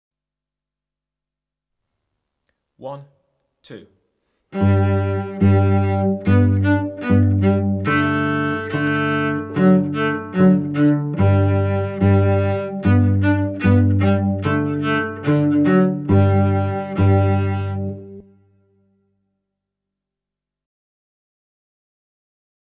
1 Bow down, O Belinda (Cello)